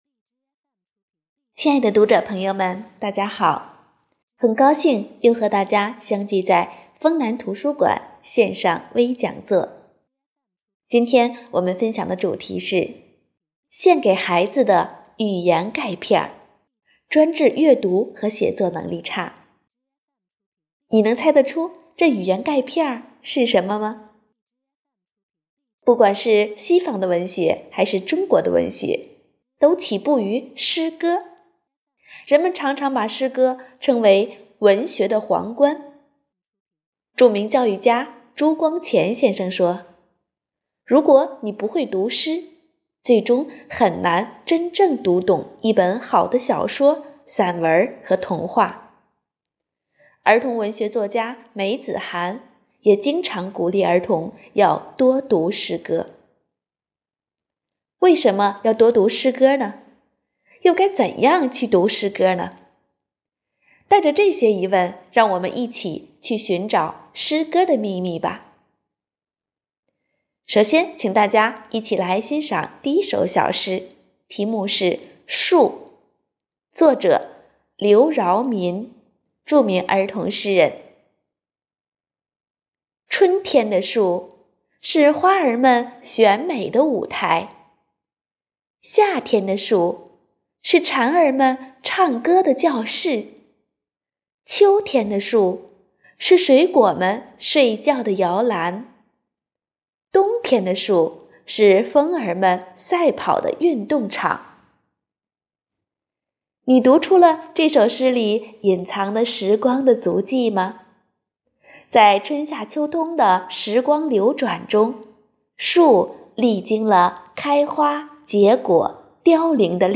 【讲座】丰图讲座 | 献给孩子的“语言钙片” 专治阅读和写作能力差
活动地点：丰南图书馆 线上活动